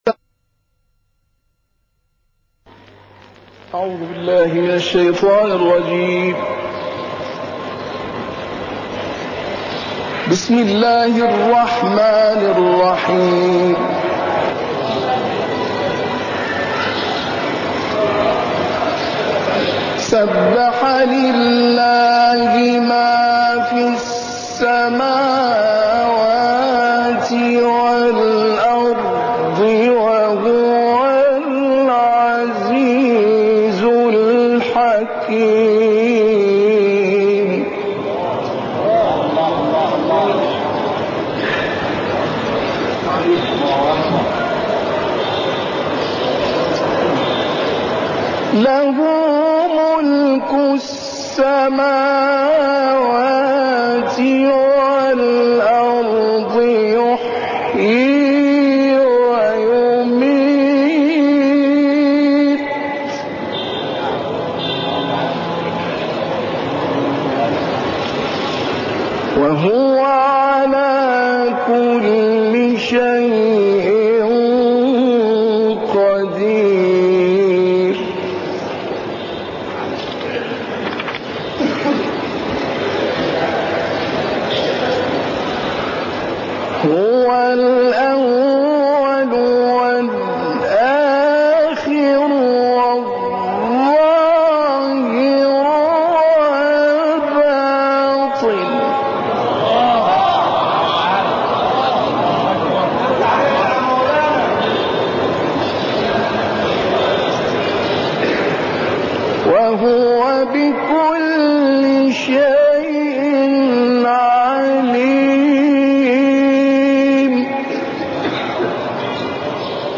تلاوت مجلسی
قاری مصری کمتر شناخته شده